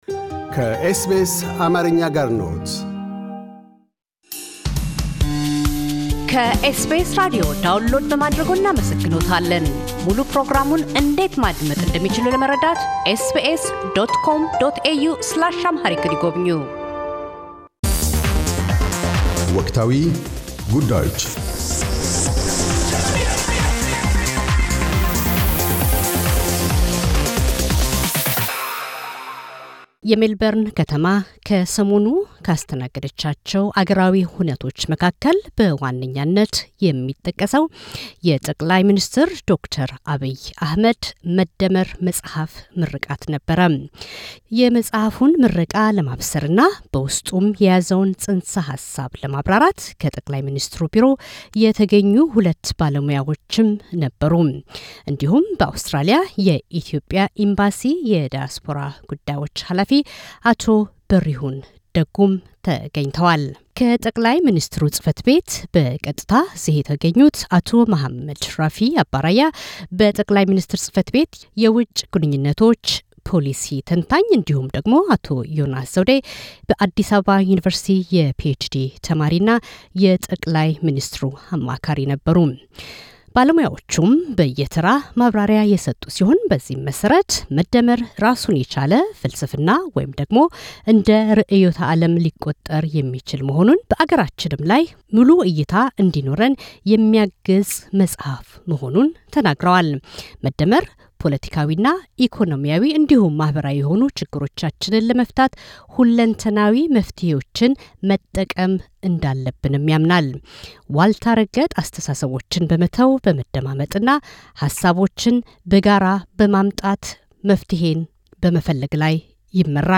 “ መደመር በሜልበርን” የመጽሃፍ ምረቃና በመርሆዎቹ እንዲሁም የዕሳቤ ባለቤትነት ላይ የተደረገ ውይይት
ከታዳሚዎች ለቀረቡት ጥያቄዎች ምላሽ ሰጥተዋል።